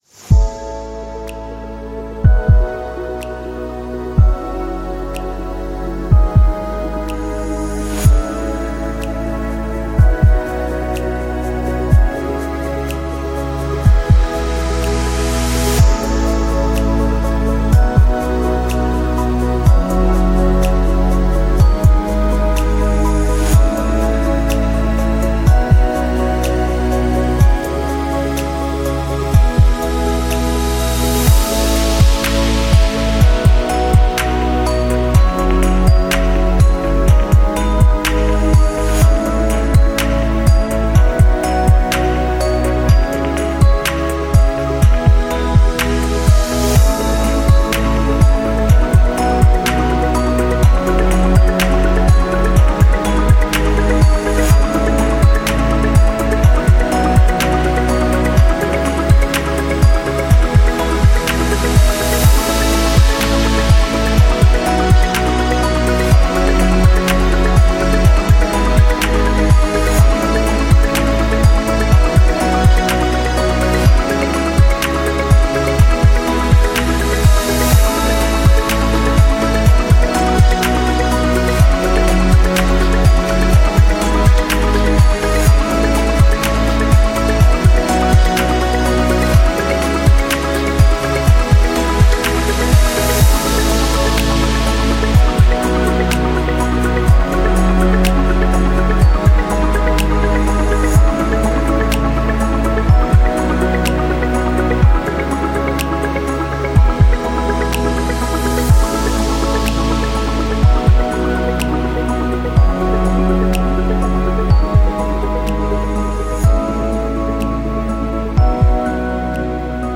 10 - Soundscape Dreamy Background